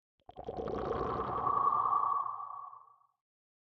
Minecraft Version Minecraft Version 1.21.5 Latest Release | Latest Snapshot 1.21.5 / assets / minecraft / sounds / block / conduit / short7.ogg Compare With Compare With Latest Release | Latest Snapshot